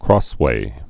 (krôswā, krŏs-)